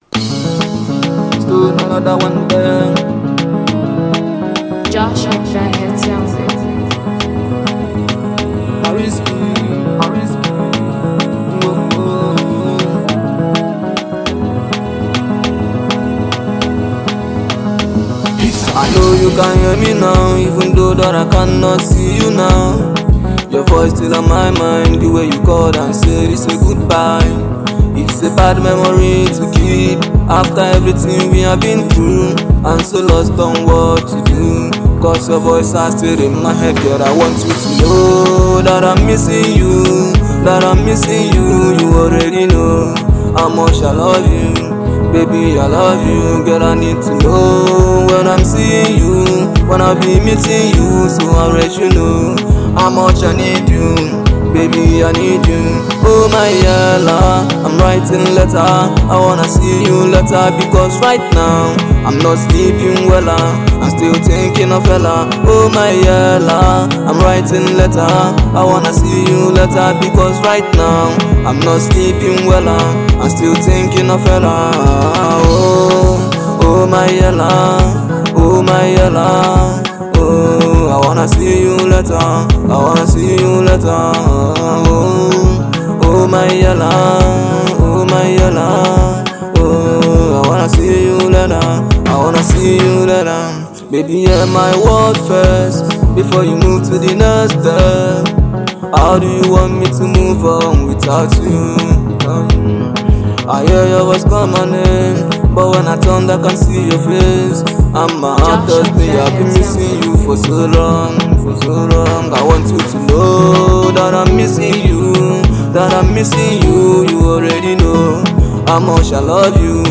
Fast Rising Afro beat singer